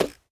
Minecraft Version Minecraft Version snapshot Latest Release | Latest Snapshot snapshot / assets / minecraft / sounds / block / bamboo / step1.ogg Compare With Compare With Latest Release | Latest Snapshot